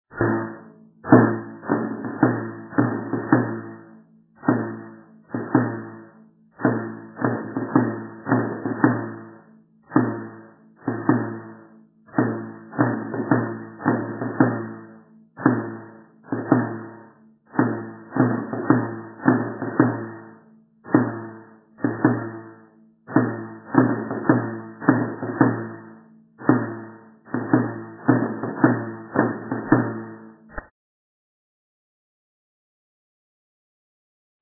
07)  Toque de procesión.